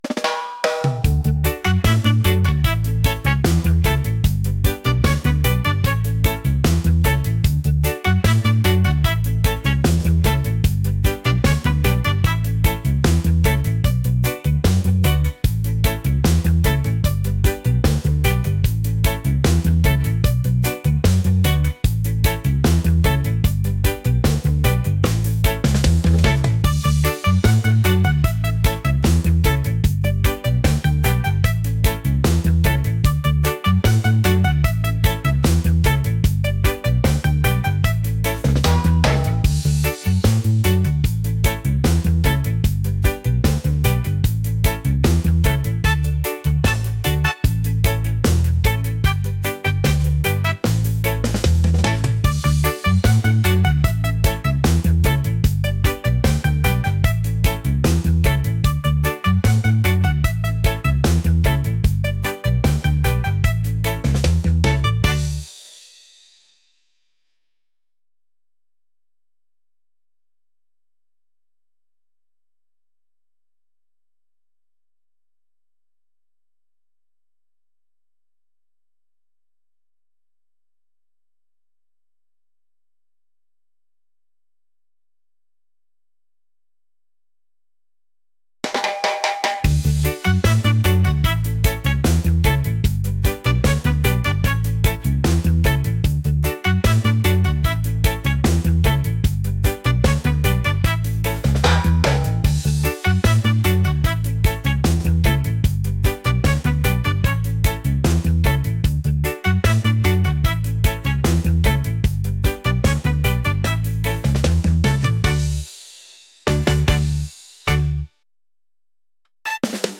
reggae | upbeat | positive